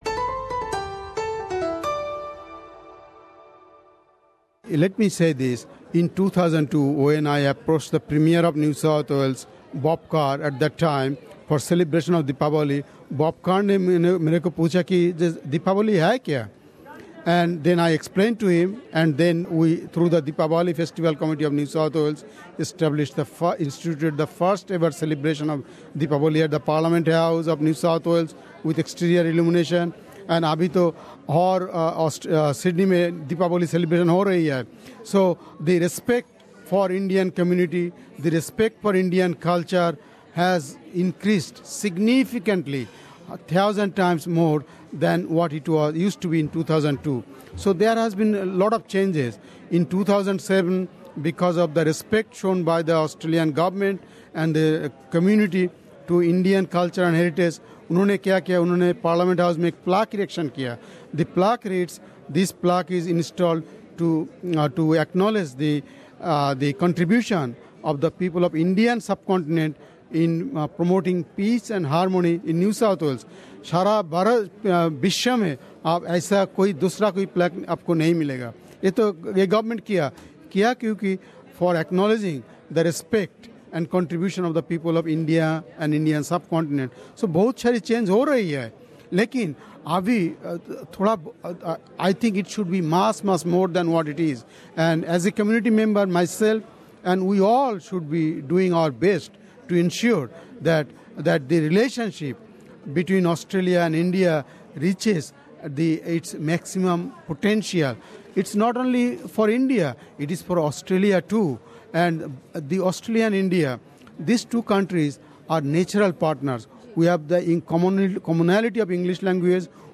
Indian-Australian Councillor from Sydney's Strathfield, Cr Raj Dutta has been in Australia for 29 years and was instrumental in initiating Diwali Celebrations in NSW Parliament. We spoke to Cr. Raj Dutta at the recent AIBC Dinner to know more about this initiative and about India-Australia relations.